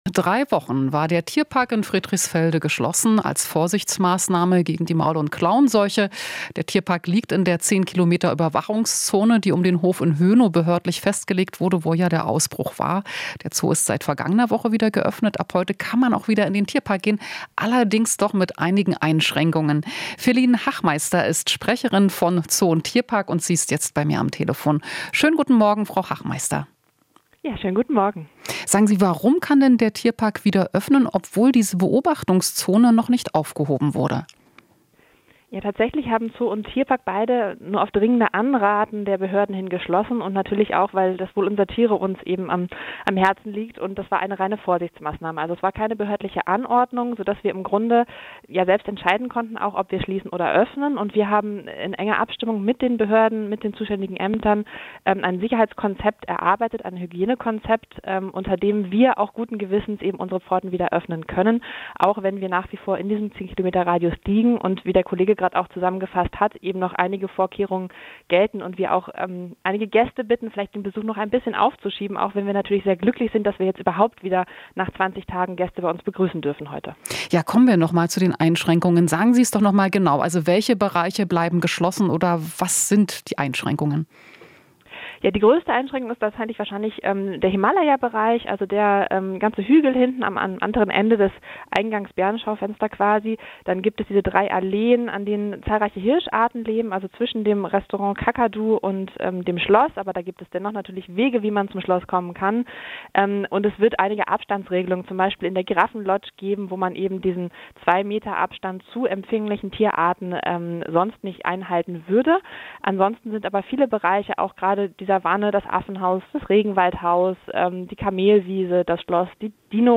Interview - Tierpark Berlin öffnet wieder - mit Hygienekonzept